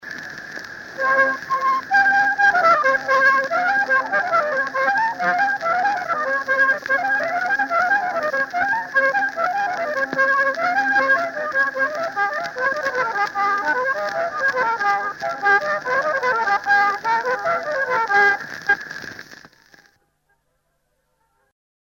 Lõõtsalugu 2